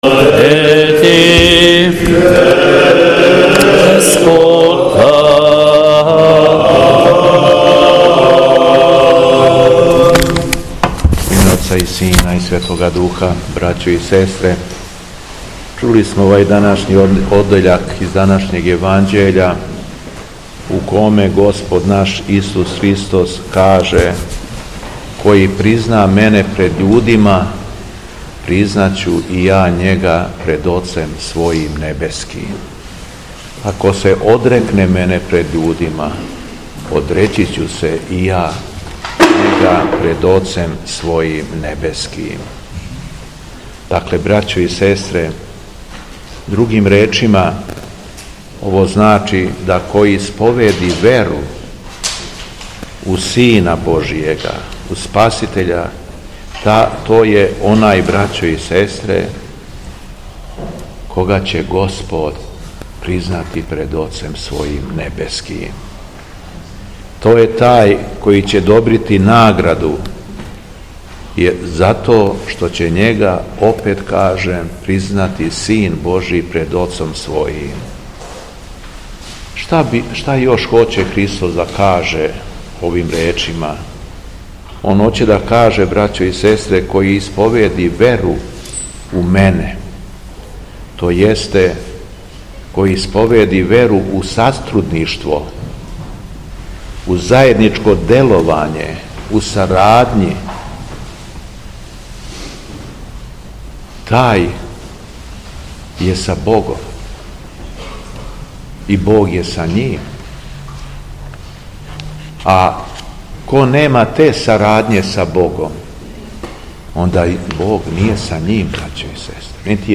СВЕТА ЛИТУРГИЈА У СТАРОЈ ЦРКВИ У КРАГУЈЕВЦУ - Епархија Шумадијска
Беседа Његовог Високопреосвештенства Митрополита шумадијског г. Јована
Беседећи верном народу Митрополит Јован је рекао: